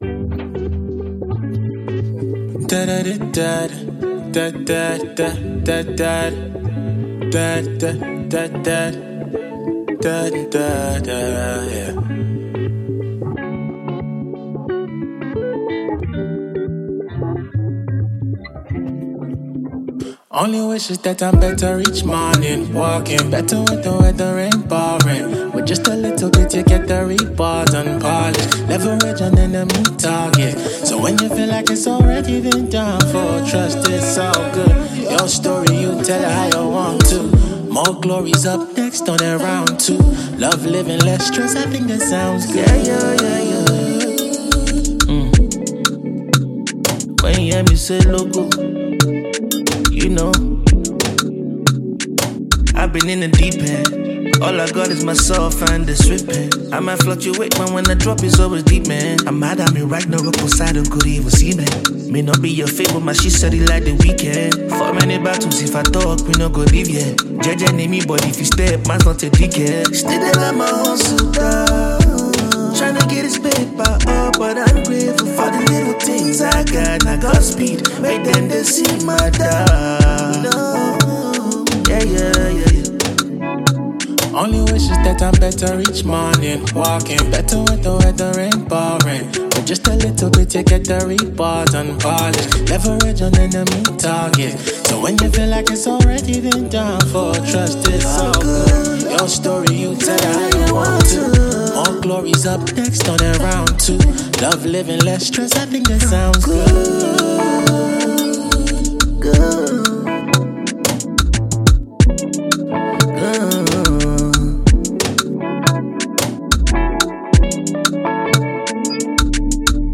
a sensational neo-soul, alternative R&B singer